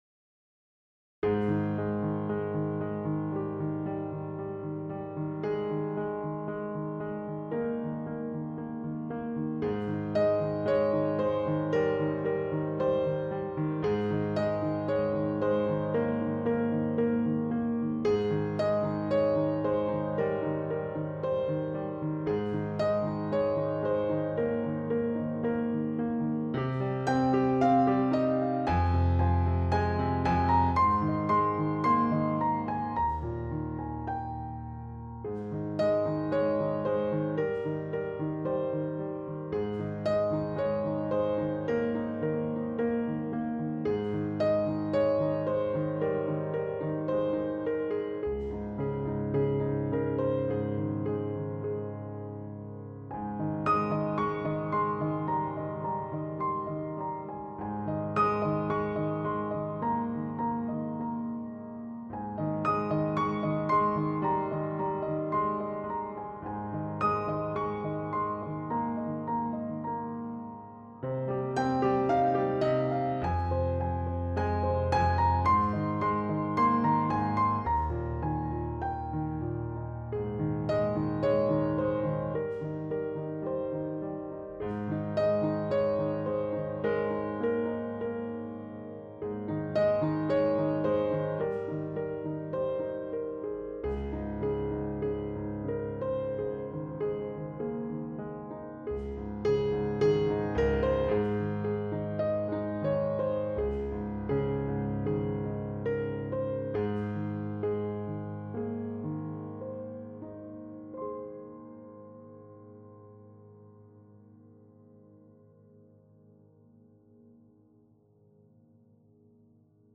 描述：基调|平静
Tag: 钢琴